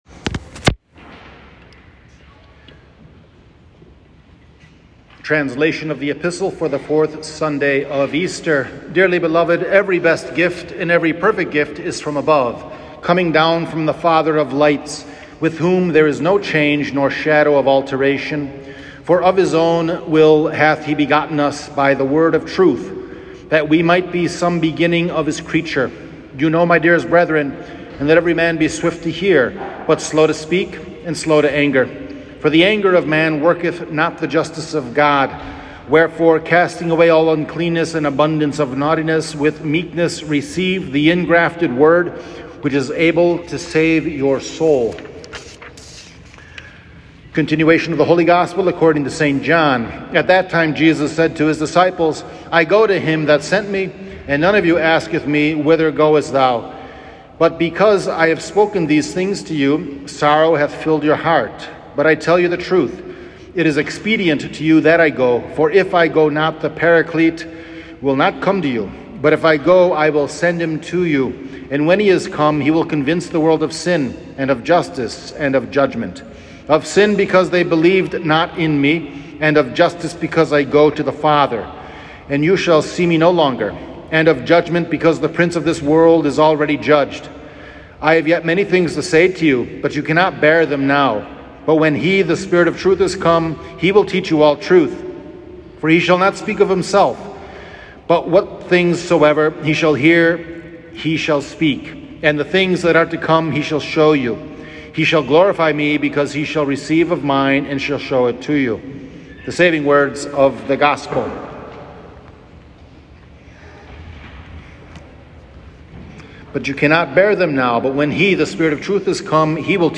Fourth Sunday After Easter — Homily